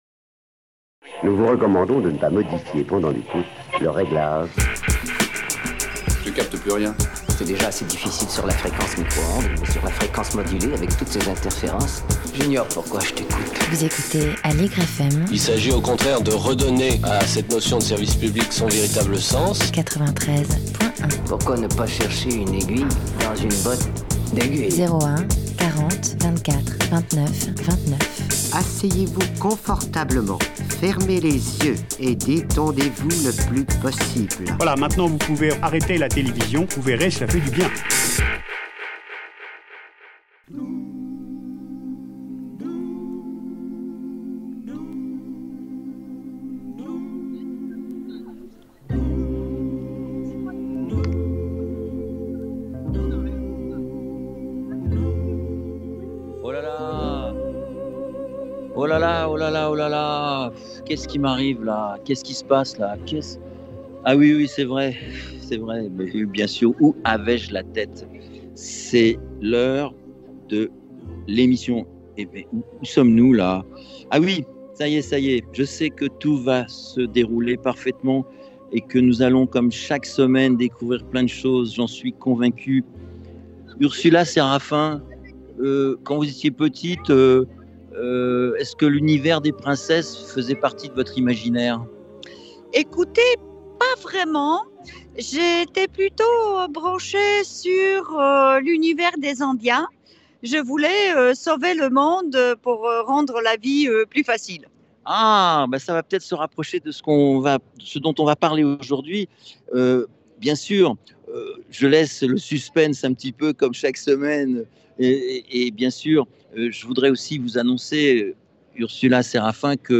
L'étincelle dans la ville # 49 - La fête de l'Europe sur le Parvis de l'Hotel de ville à Paris